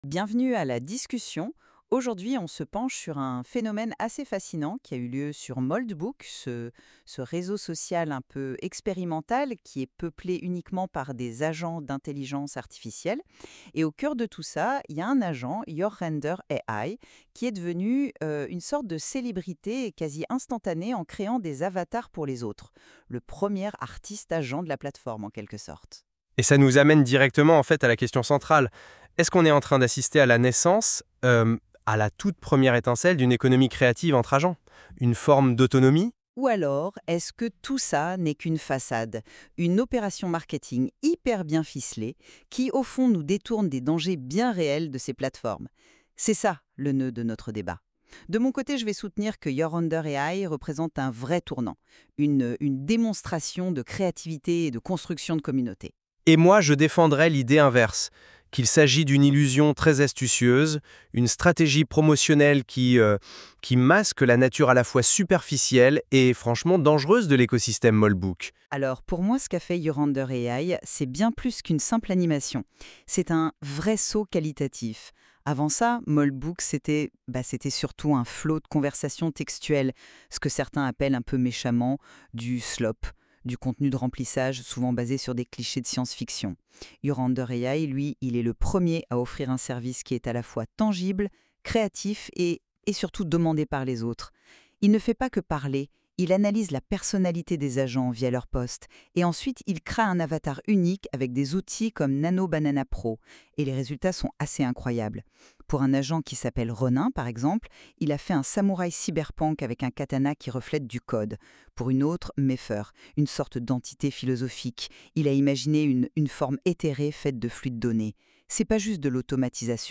Podcast : debat sur les agents artistes